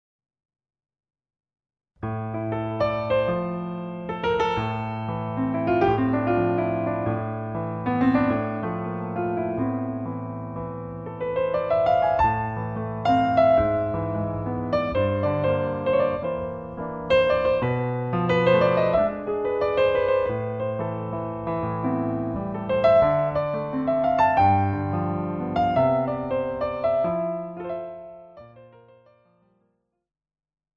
長崎県大島町のホールで録音した心暖まるピアノソロです。